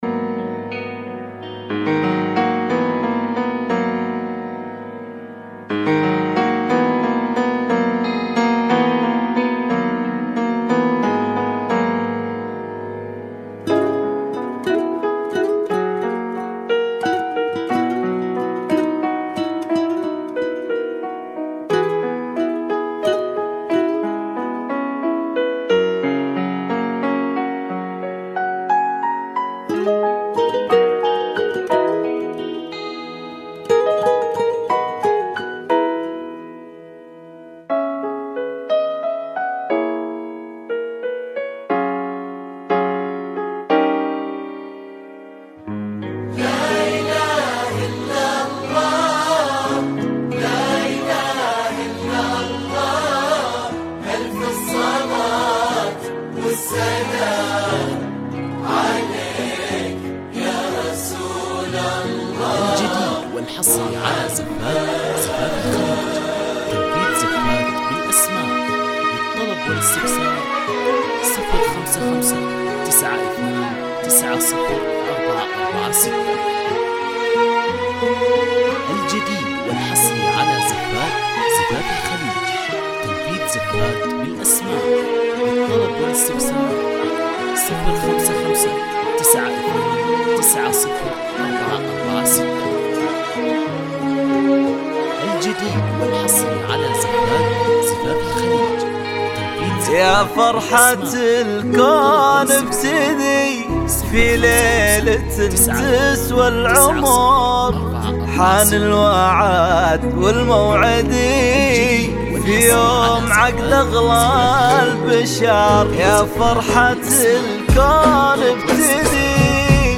زفة ملكه عقد قران